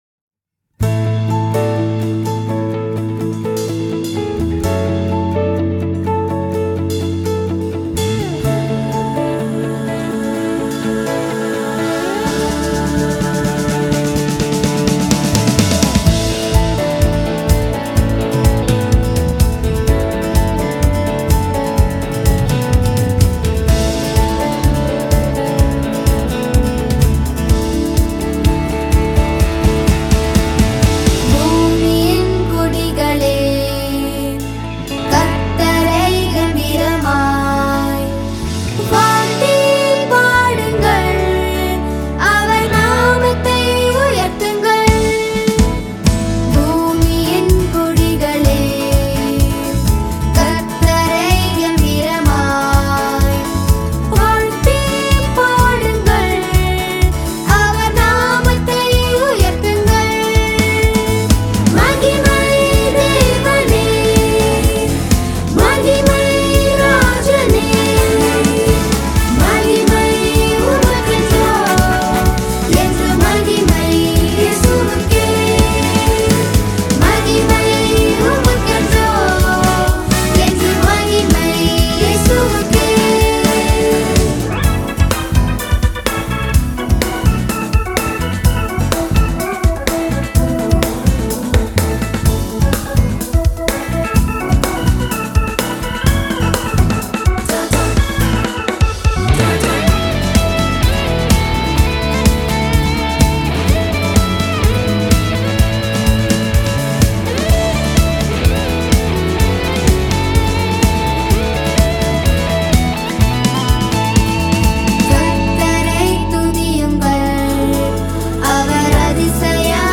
gospel album
Royalty free Christian music.